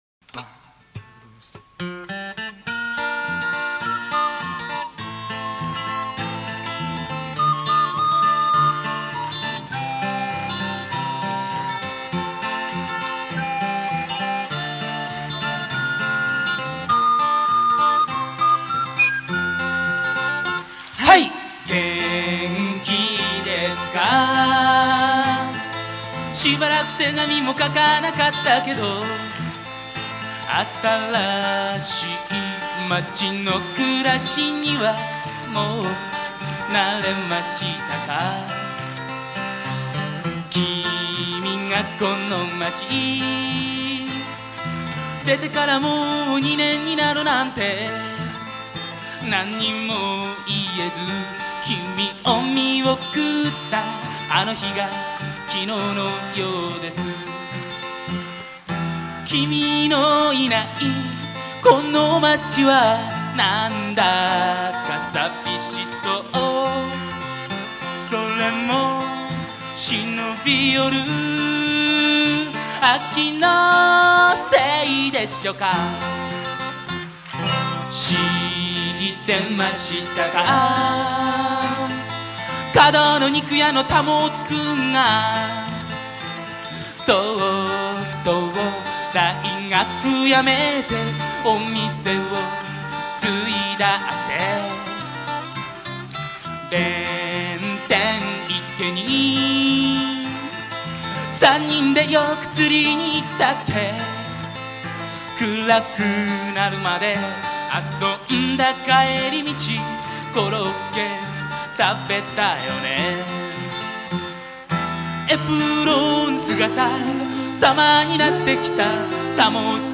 場所：神奈川公会堂
イベント名：横浜ふぉーく村コンサート